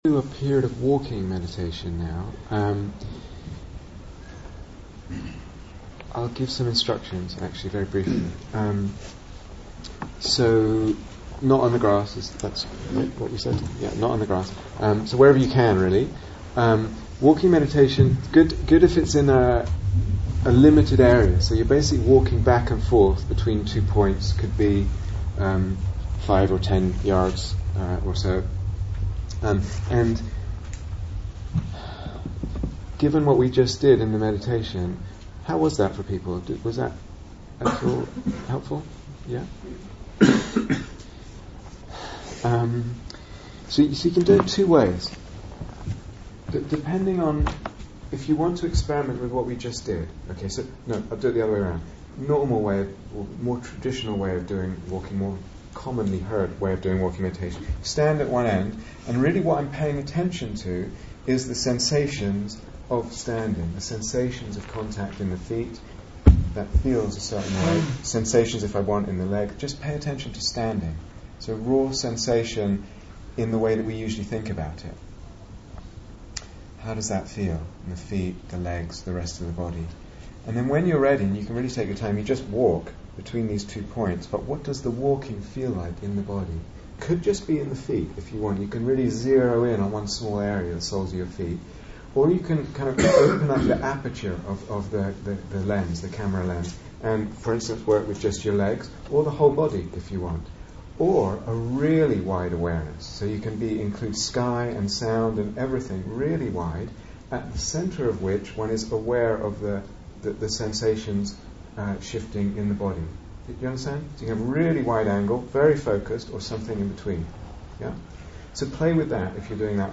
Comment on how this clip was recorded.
Retreat/SeriesDay Retreat, London Insight 2014